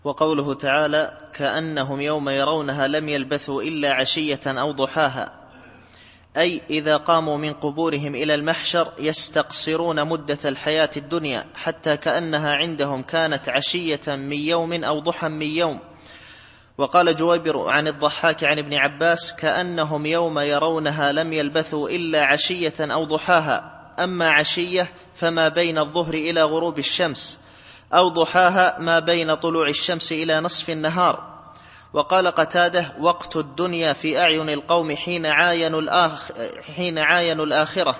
التفسير الصوتي [النازعات / 46]